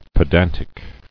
[pe·dan·tic]